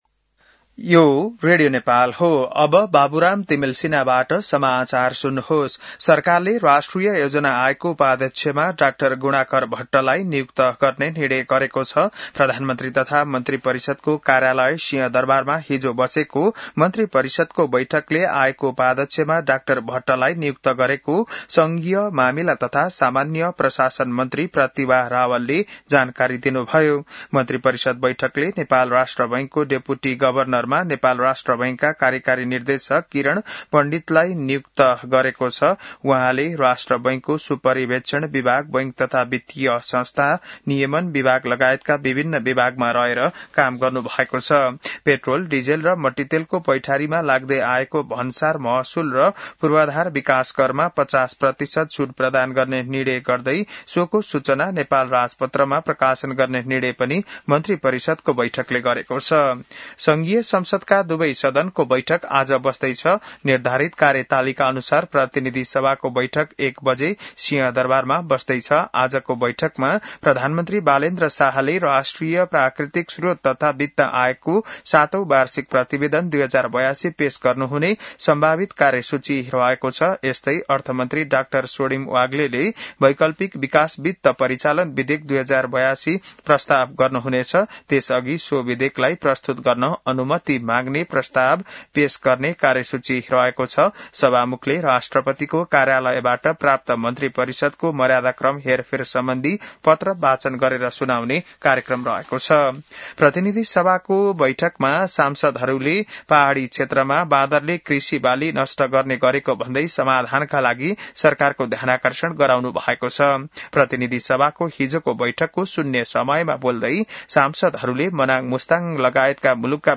An online outlet of Nepal's national radio broadcaster
बिहान १० बजेको नेपाली समाचार : २५ चैत , २०८२